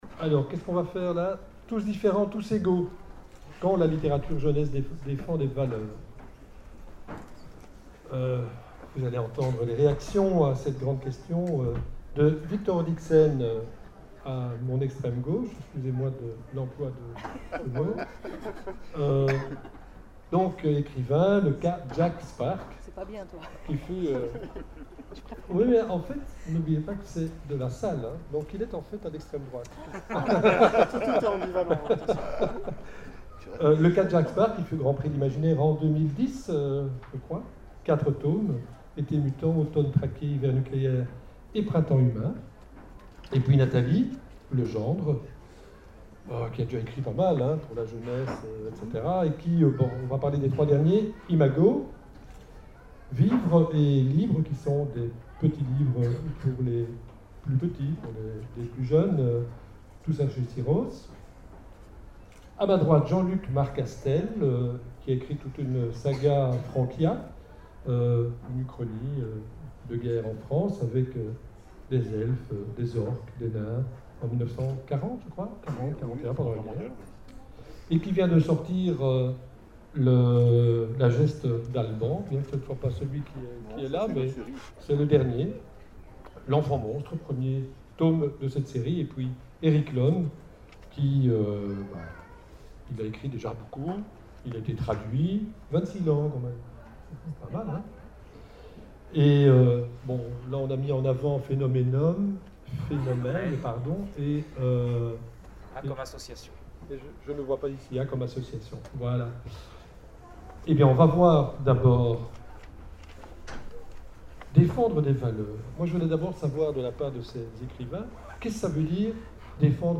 Imaginales 2012 : conférence Tous différents, tous égaux !